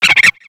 Cri de Feuillajou dans Pokémon X et Y.